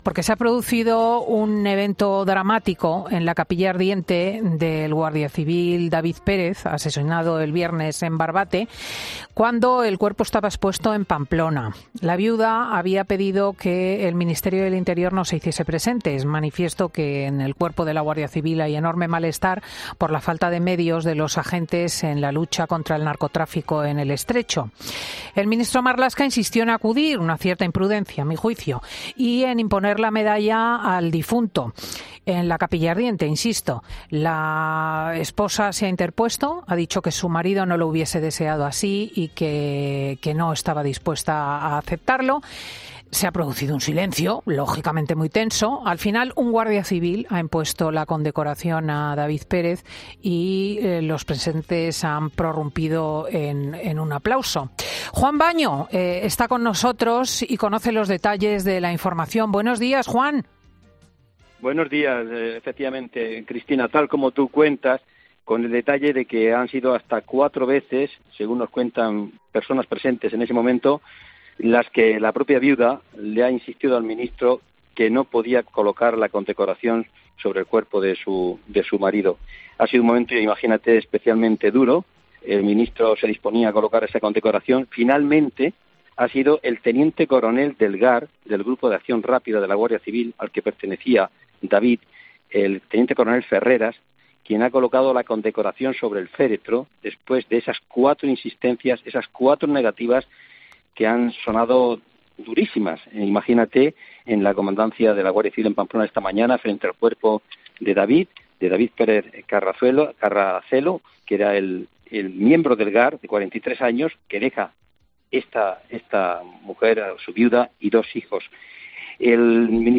Momentos de tensión en la capilla ardiente de uno de los guardias civiles asesinados en Barbate